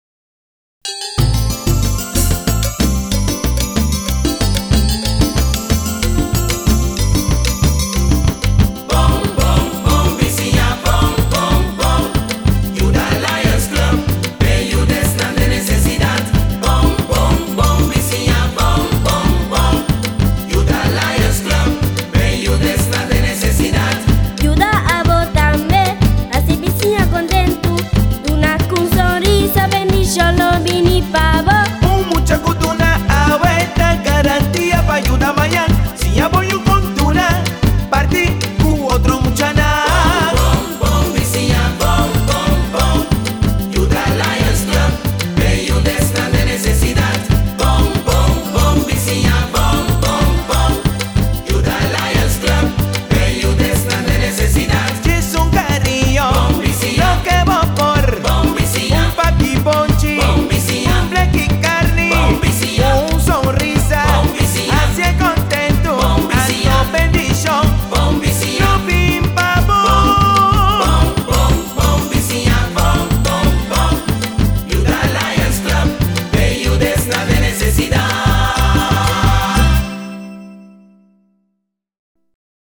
jingle